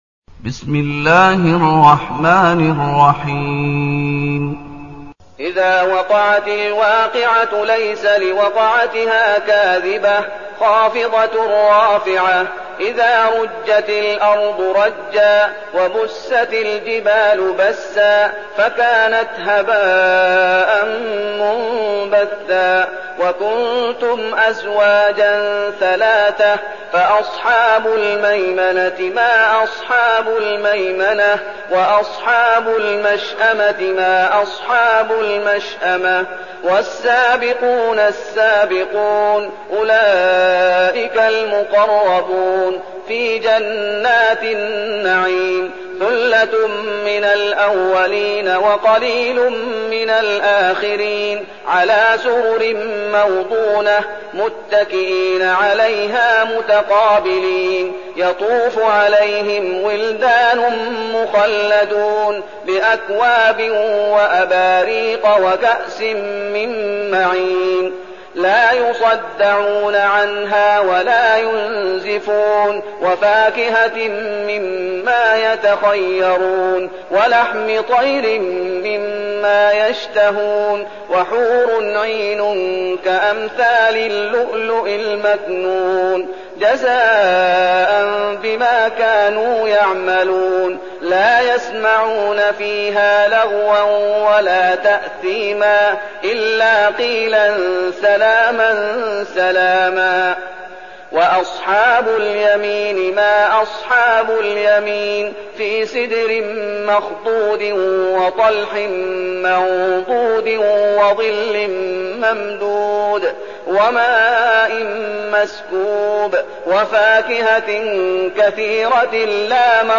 المكان: المسجد النبوي الشيخ: فضيلة الشيخ محمد أيوب فضيلة الشيخ محمد أيوب الواقعة